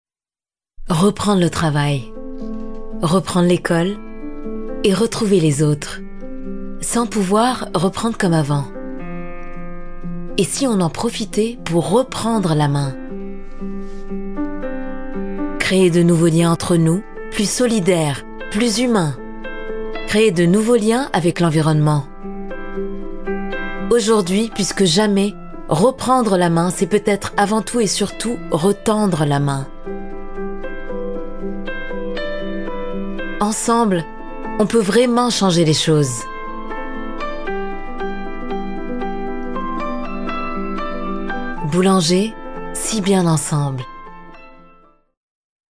Pub DM - Voix chaleureuse